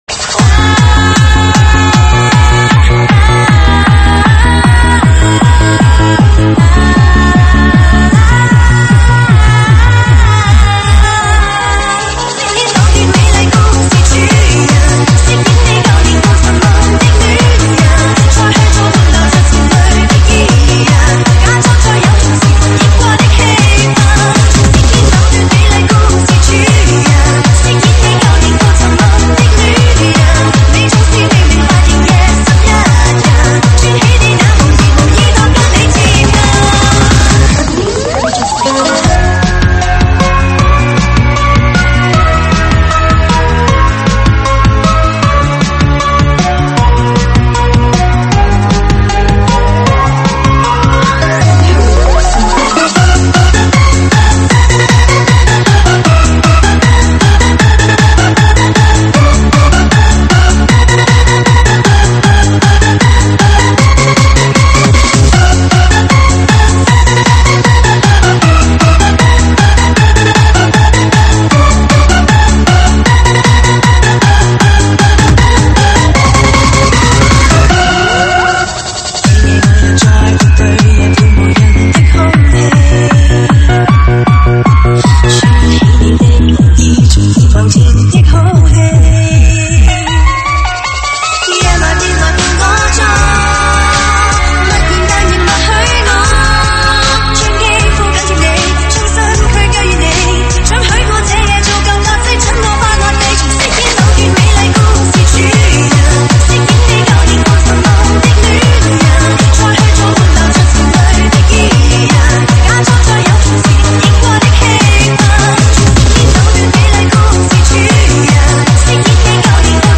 舞曲类别：周榜单